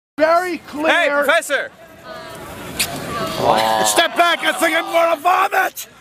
Play, download and share step back original sound button!!!!
step-back-i-think-im-gonna-vomit-original_YRA5k2C.mp3